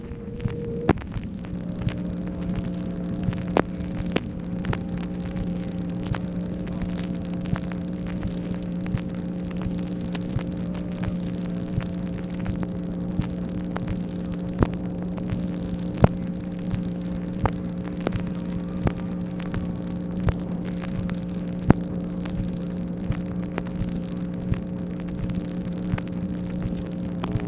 OFFICE NOISE, May 14, 1965
Secret White House Tapes | Lyndon B. Johnson Presidency